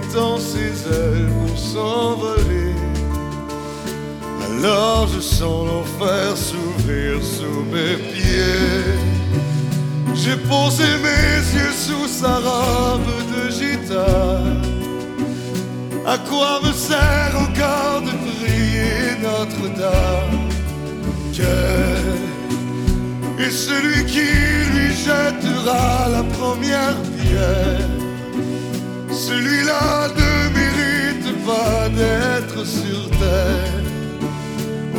Жанр: Поп / Музыка из фильмов / Саундтреки